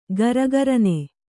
♪ garagarane